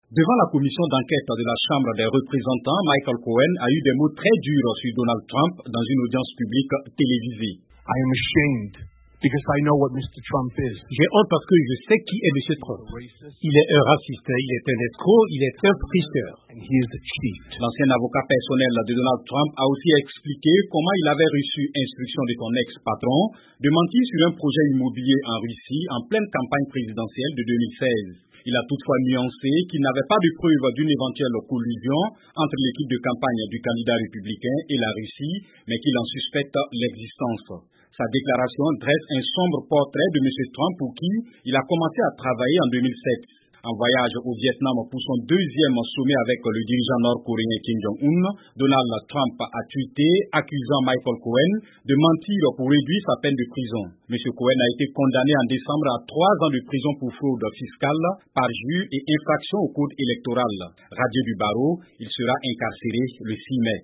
Témoignage de Michael Cohen, ancien avocat personnel de Donald Trump, devant le Congrès